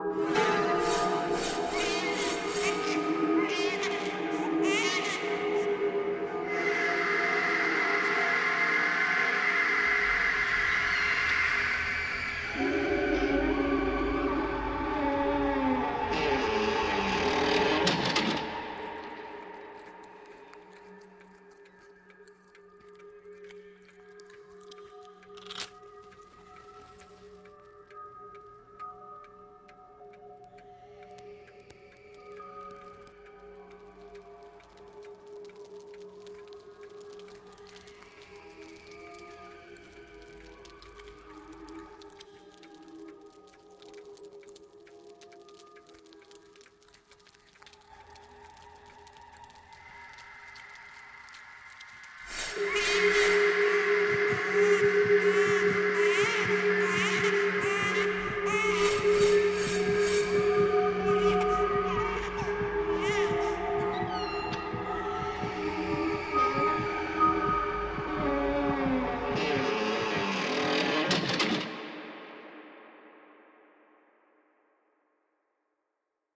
Soundarbeit